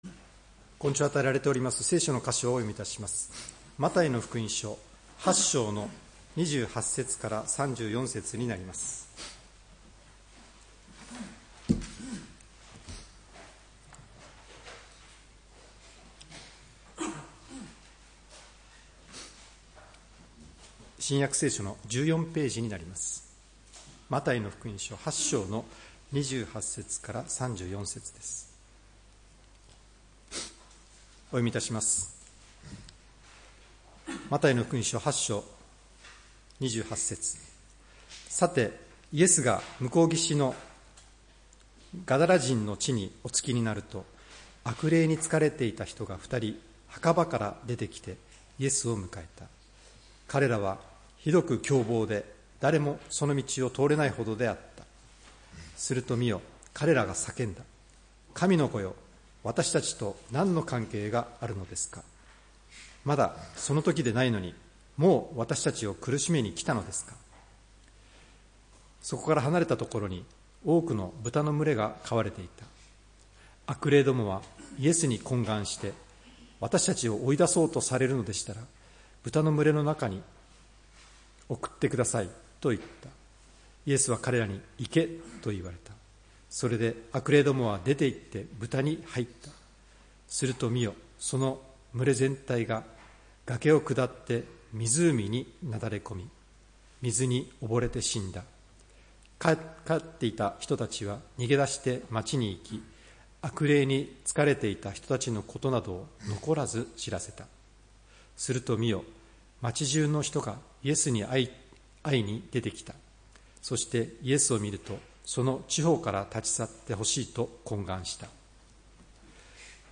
礼拝メッセージ「救われた者として」（10月19日）